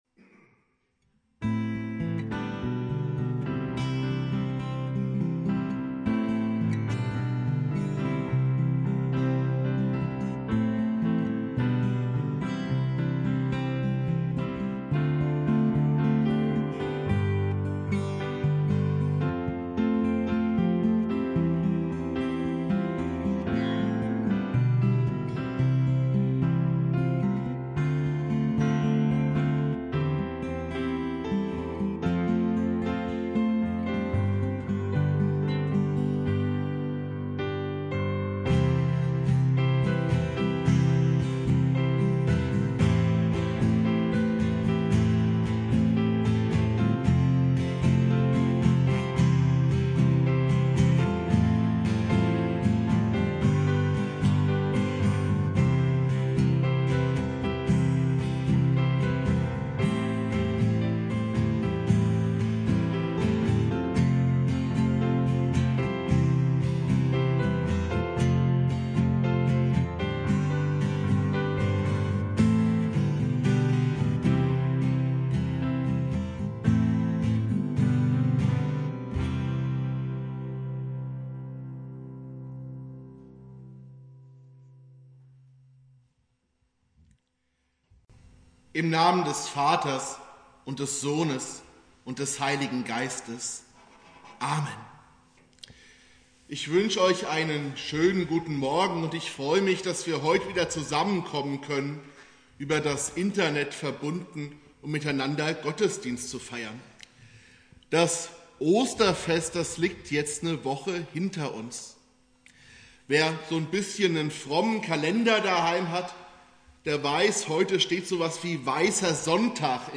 Predigt
Gottesdienst komplett